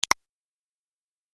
جلوه های صوتی
دانلود صدای کلیک 17 از ساعد نیوز با لینک مستقیم و کیفیت بالا